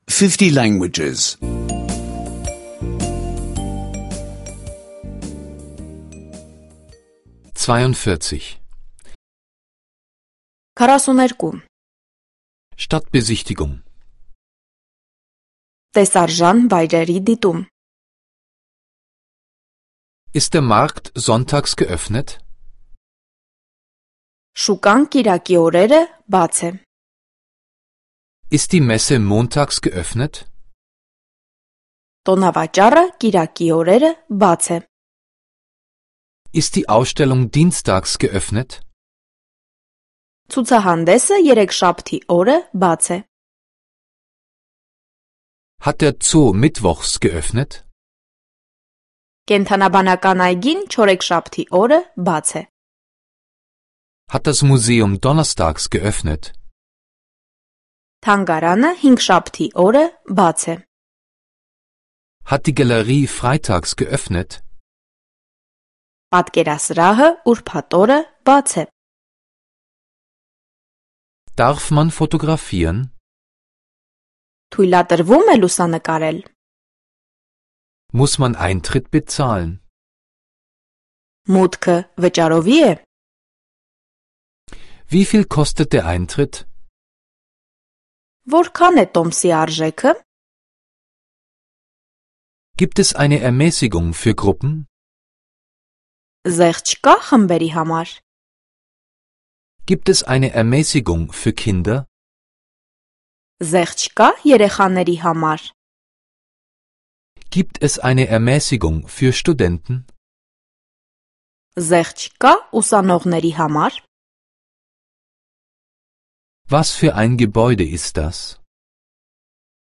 Armenische Audio-Lektionen, die Sie kostenlos online anhören können.